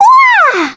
Annette-Vox_Happy1_kr.wav